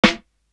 Pointer Snare.wav